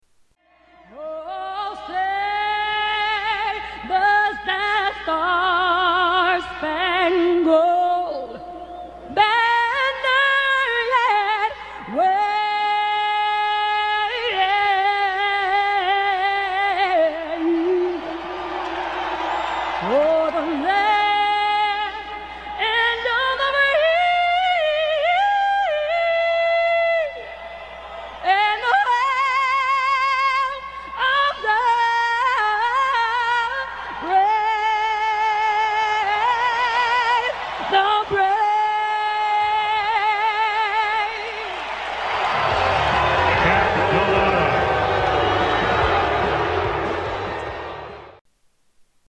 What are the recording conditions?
Tags: Music Most Embarrassing Live Performances of All Time Live Performances Singers Emabarrassing